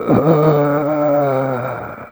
c_zombim2_dead.wav